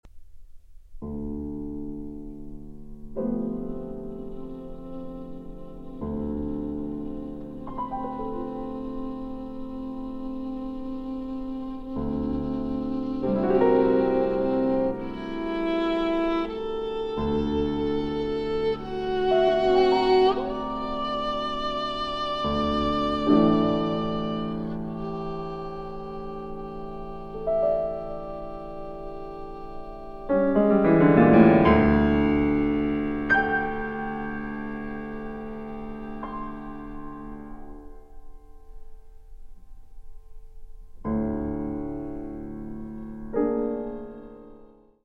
Suspended, fluid (3:24)